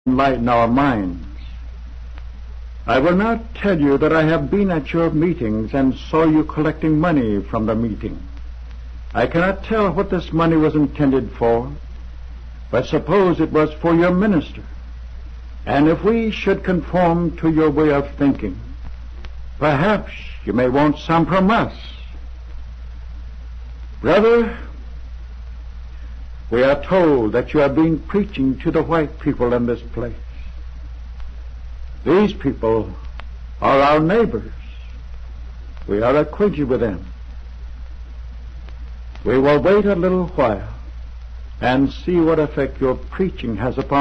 Speech delivered 1805 Buffalo Grove New York 9